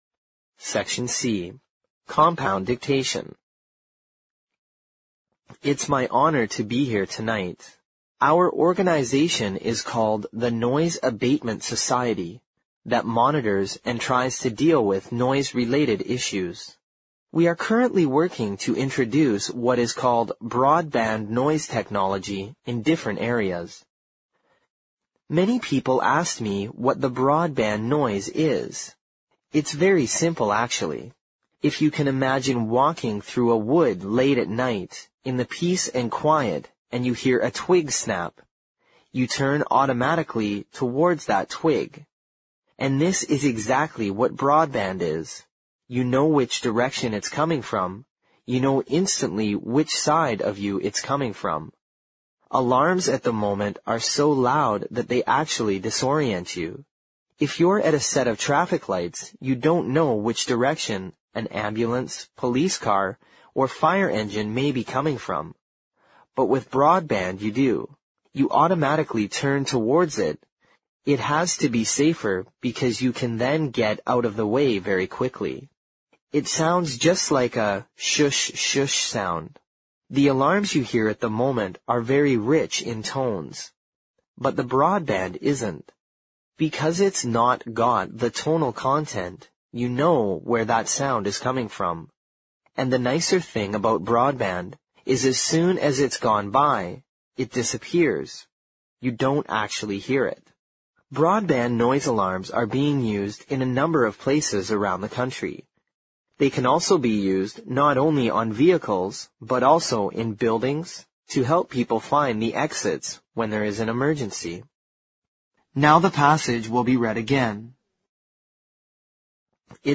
Compound Dictation